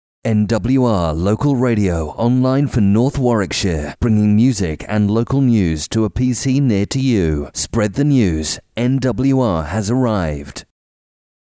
nwr intro male.mp3